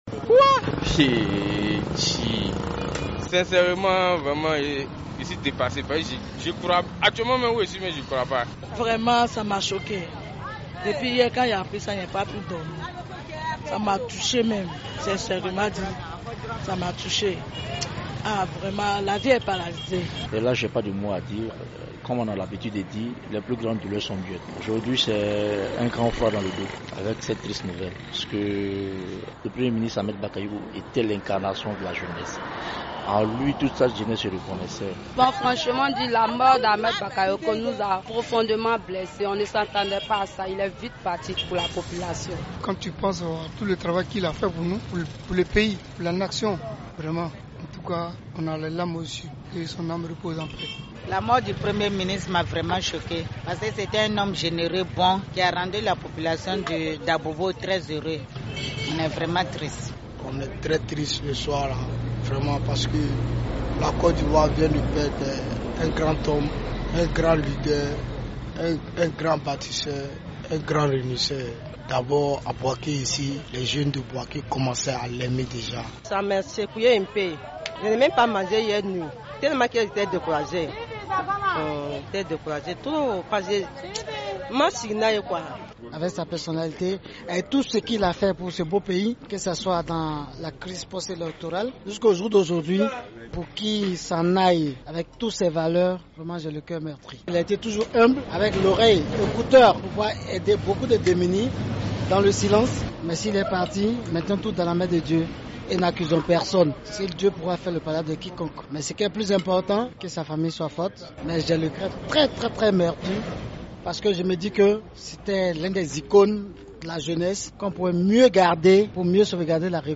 Le premier ministre, ministre de la défense ivoirienne est décédé hier mercredi 10 mars 2021 des suites d’un cancer en Allemagne. Nous avons donc des réactions des populations ivoiriennes